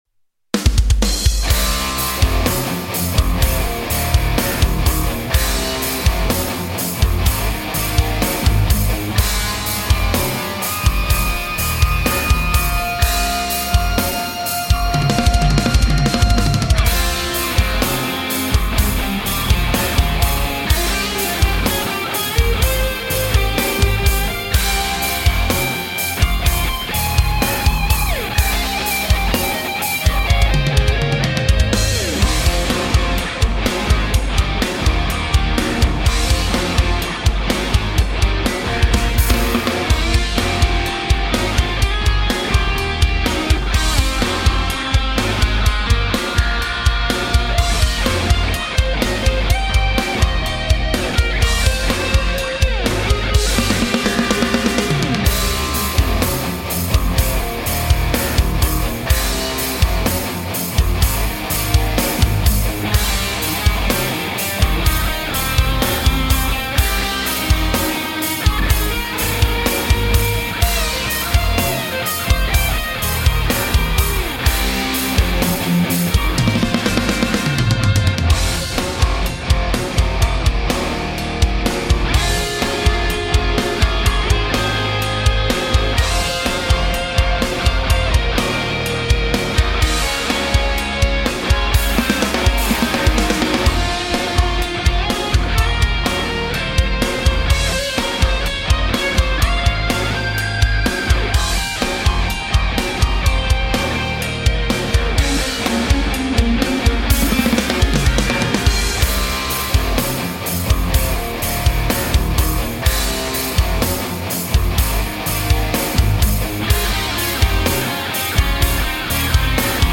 Soundtrack